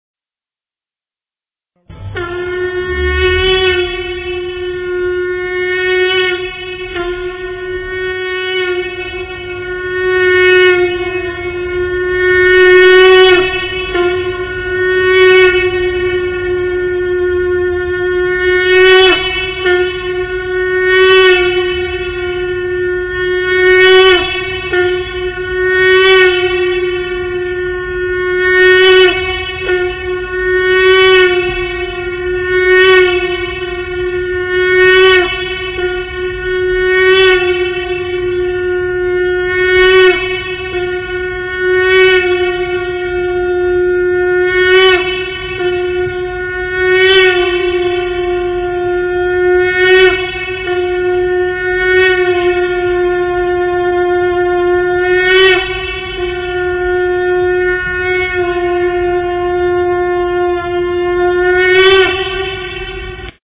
shofar.mp3